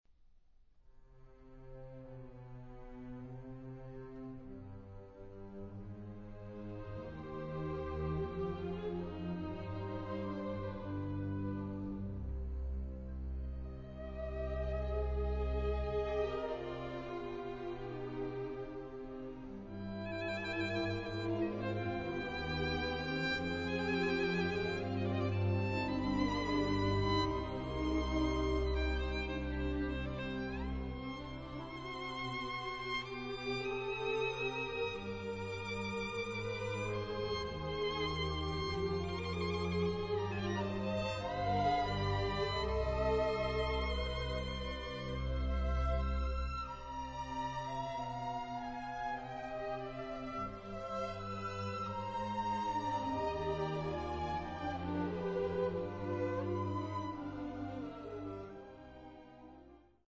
BBC Studio 1, Maida Vale